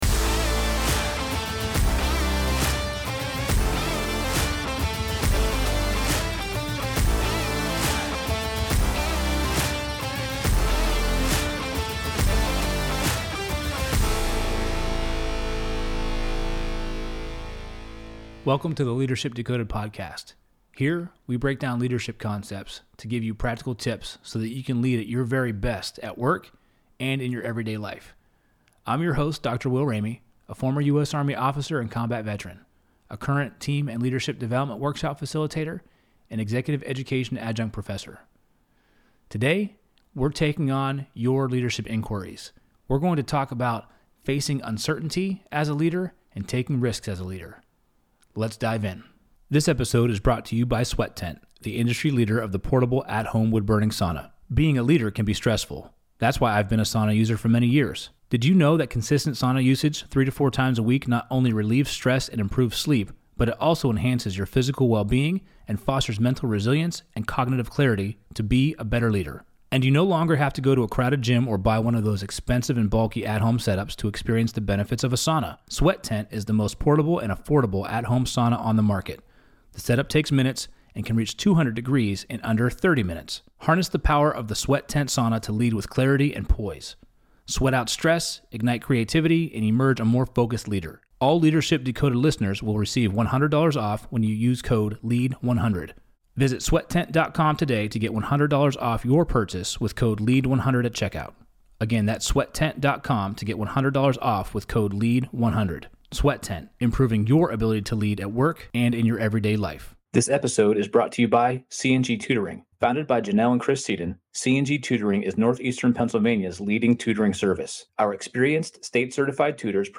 Ask Me Anything Leadership Q&A | Ep.049